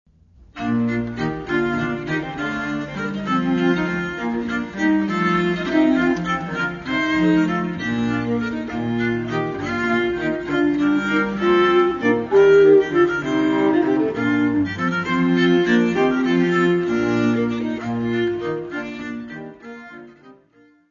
Mary's music : Songs and dances from the time of Mary Queen of Scots
Área:  Música Clássica